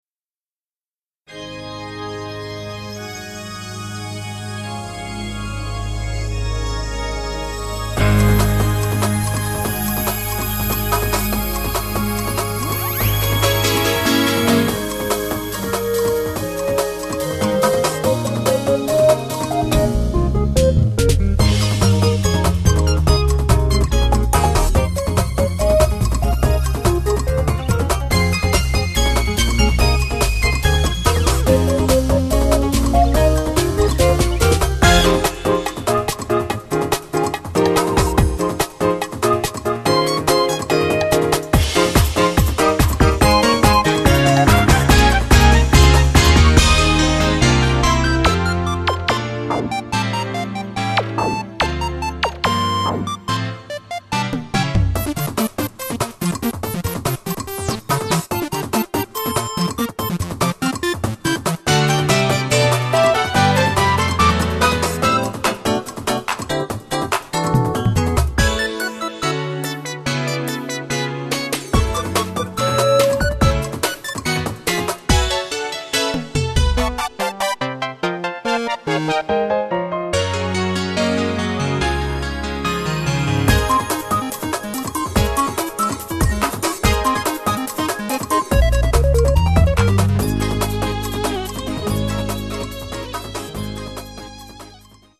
BPM72-143
Audio QualityPerfect (High Quality)
It's a very laid back techno song